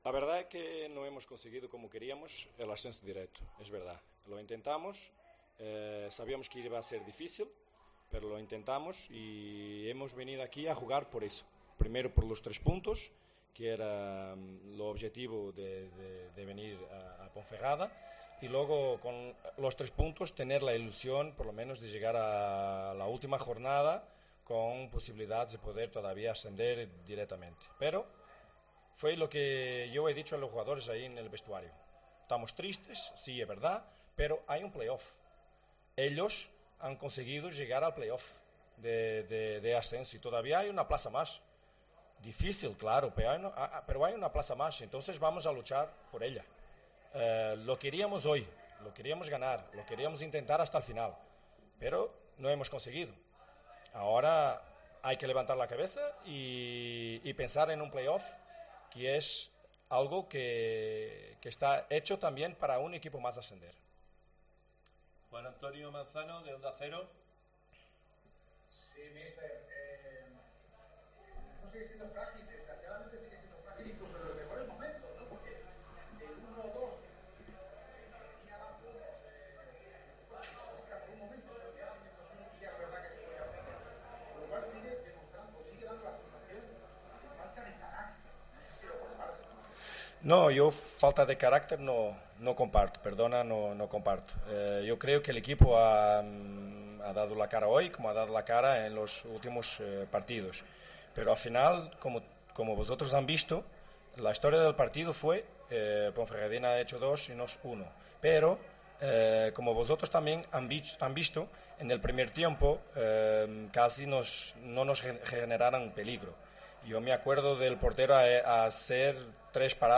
AUDIO: Escucha aquí las palabras del entrenador del Almería tras derrota en El Toralín de Ponferrada 2-1 ante la Deportiva